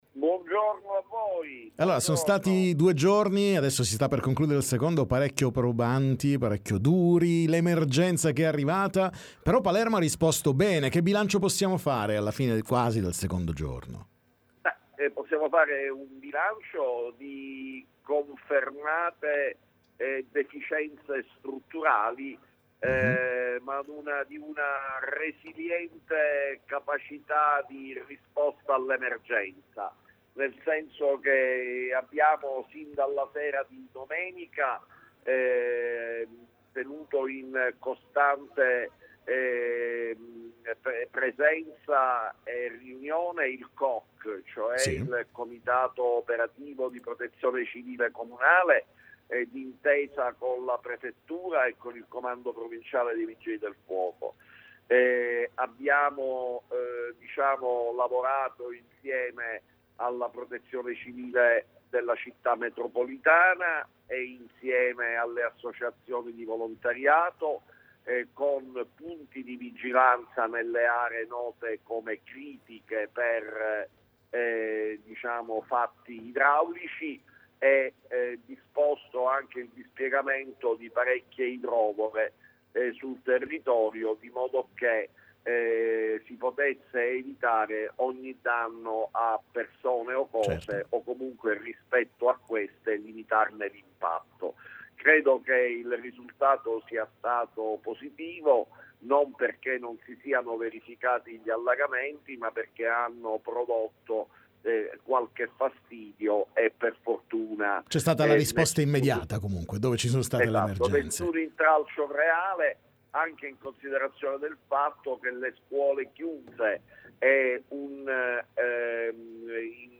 TM Intervista Roberto Lagalla